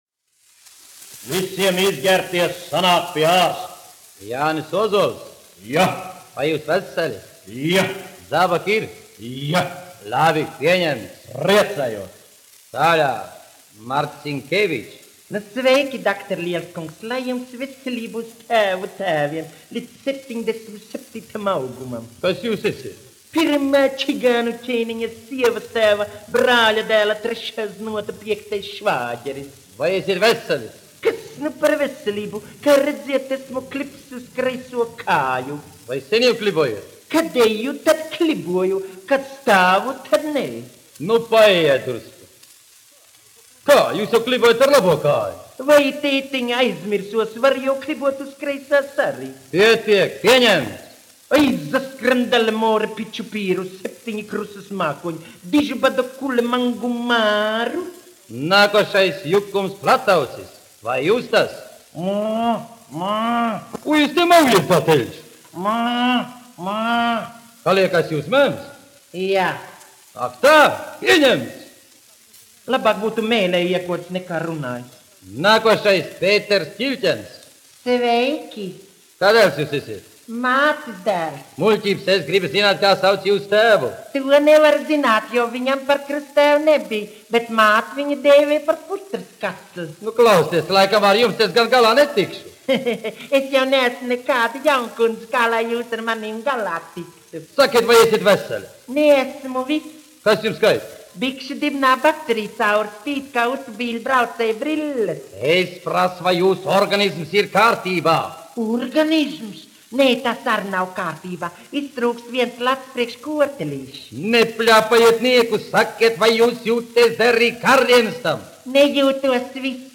Mobilizācijas komisija : komiska saruna
Brāļi Laivinieki (mūzikas grupa), izpildītājs
1 skpl. : analogs, 78 apgr/min, mono ; 25 cm
Latvijas vēsturiskie šellaka skaņuplašu ieraksti (Kolekcija)